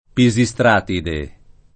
[ pi @ i S tr # tide ]